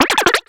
Cri de Lépidonille dans Pokémon X et Y.